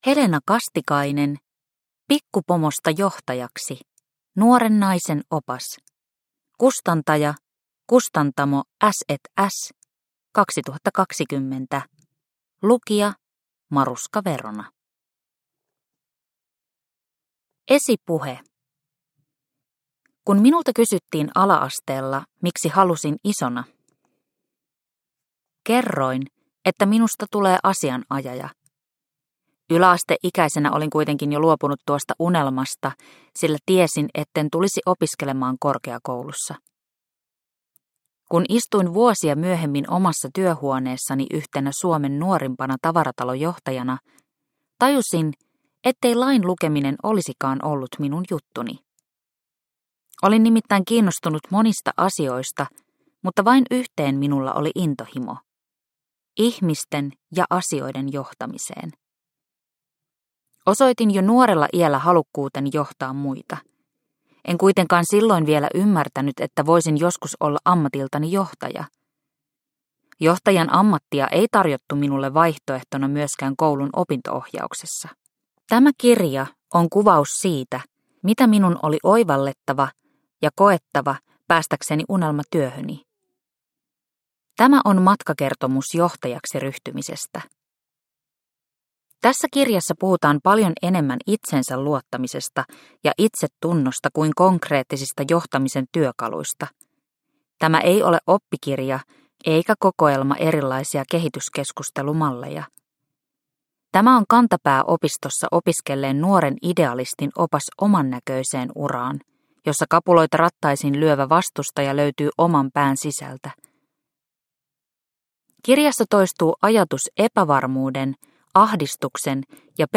Pikkupomosta johtajaksi – Ljudbok – Laddas ner